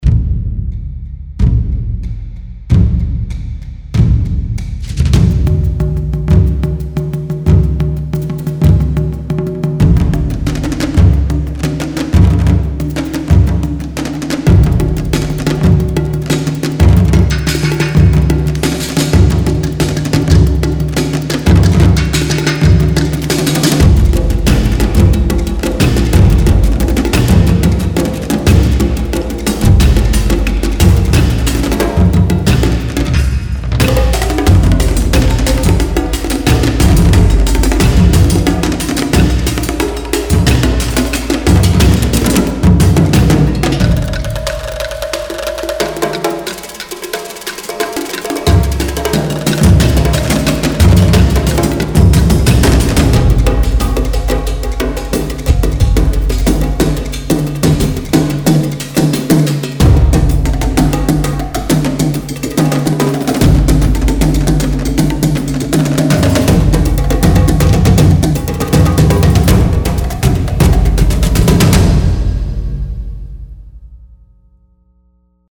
Strezov Sampling Taikos X3M是一款超级可玩的电影太鼓乐器，特别注重快速演奏和有机永恒的声音。
录制在Sofia Session Studio进行，我们还录制了以前的X3M库，以便进行平滑和轻松的分层。